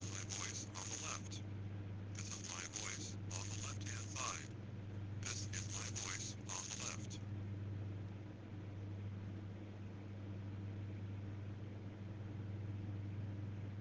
AUDIO + NOISE
For now we are getting little bit noise as we increase the volume.
PFA original and captured audio.